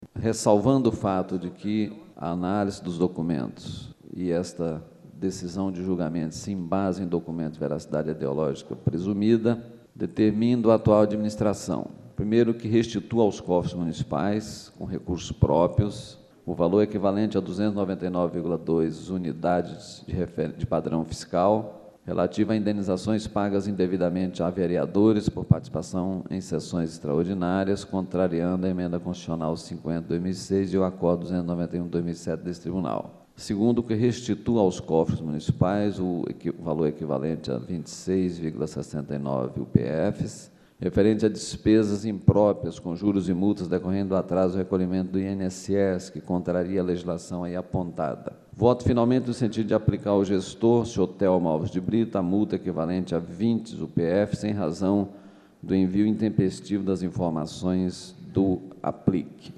Sonora: Valter albano – conselheiro TCE-MT